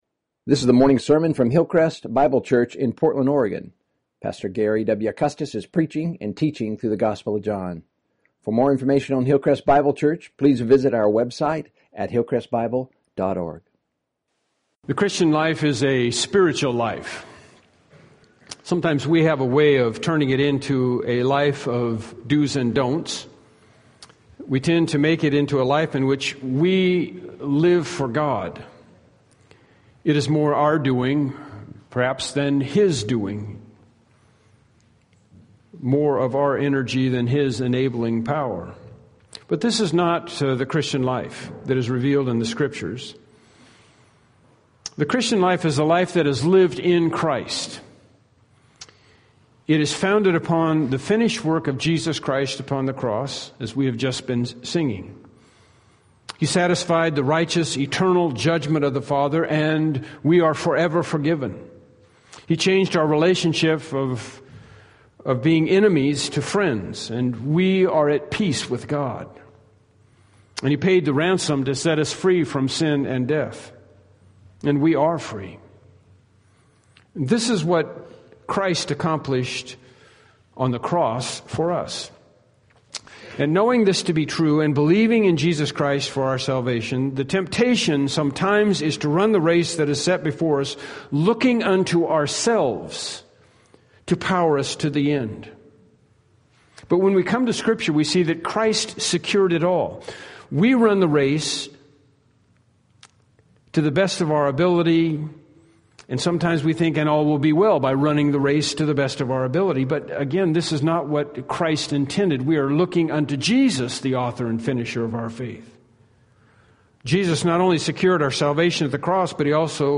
John 15:6-8 Service Type: Evening Worship Service « “Weakness and Strength” “Abiding in God’s Love” »